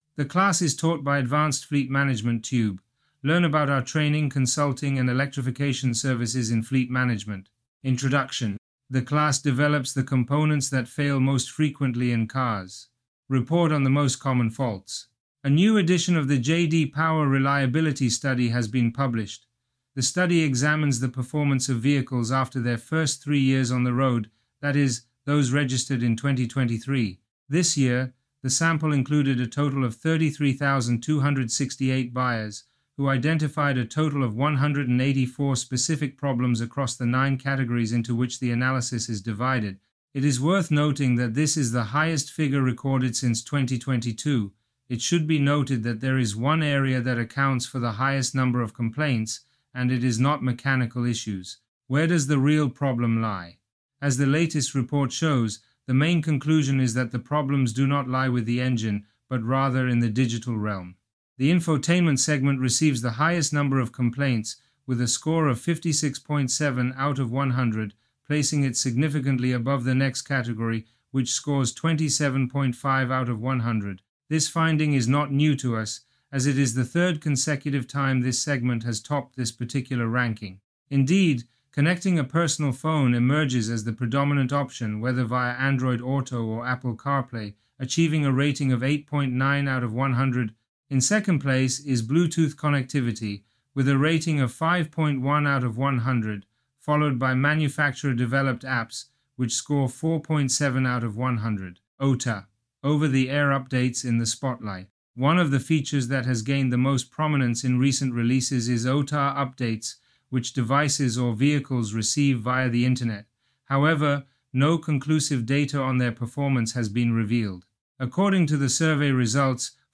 Online class